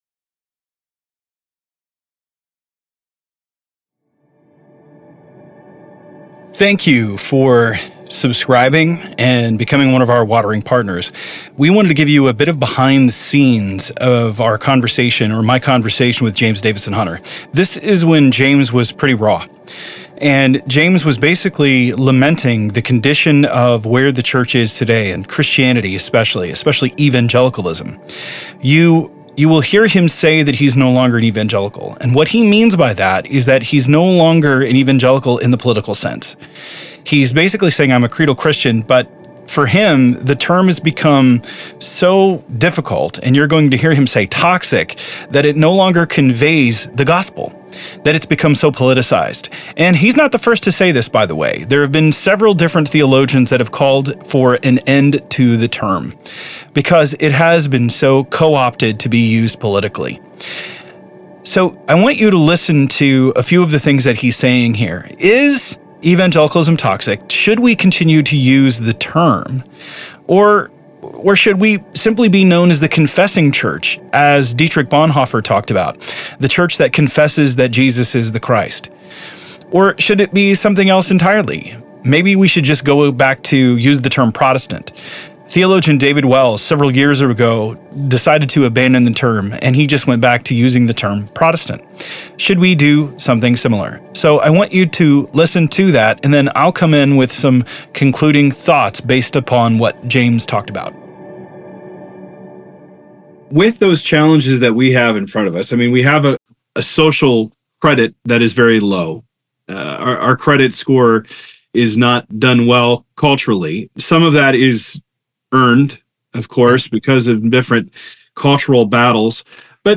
The dialogue presented herein elucidates the profound contention surrounding the term "evangelical," which has increasingly been perceived as toxic due to its entanglement with political aspirations, as articulated by James Davison Hunter. He posits that the conflation of faith and nationalism has led to an erosion of the essence of Christianity, transforming it into a socio-political movement rather than a genuine expression of creedal belief.